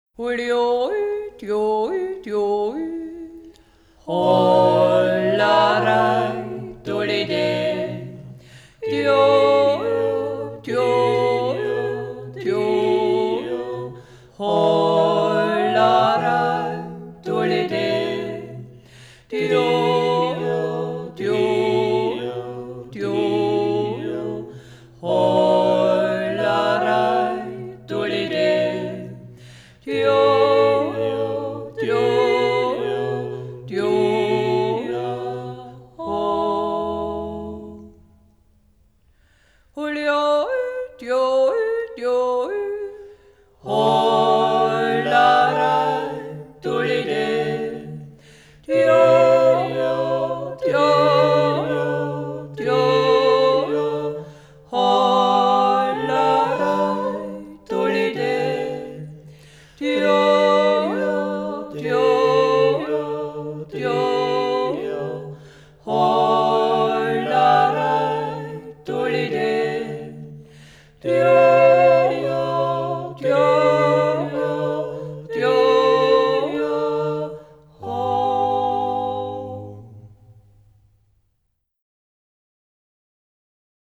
CD 1_Titel 11: WeXel oder Die Musik einer Landschaft Teil 2.1 - Das Weltliche Lied - Ungeradtaktig: Jodler und Jodler-Lied – Ungeradtaktig (E-BOOK - o:1622)